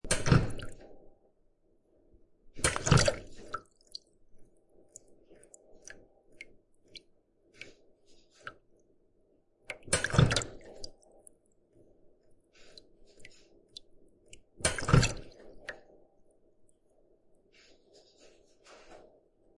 Button Click Switch Valve Water Drips
button-click-switch-valve-water-drips-30548.mp3